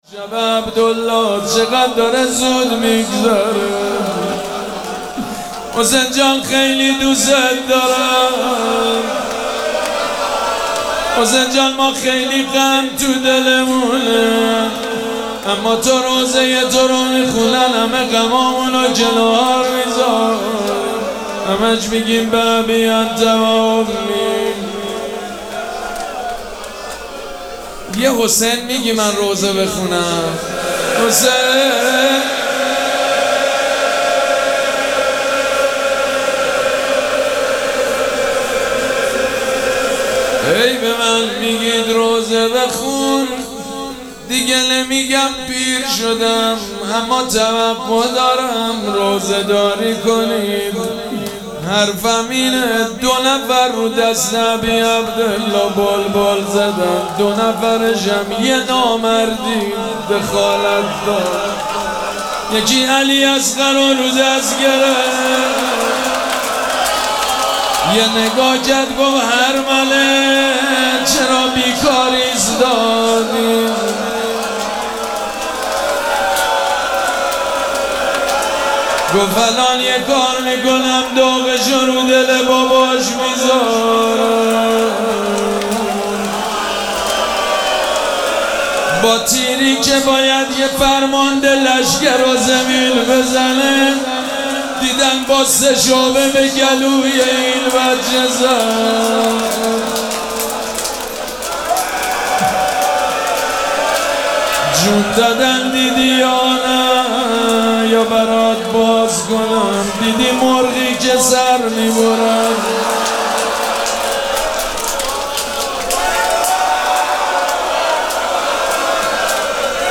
مراسم عزاداری شب پنجم محرم الحرام ۱۴۴۷
روضه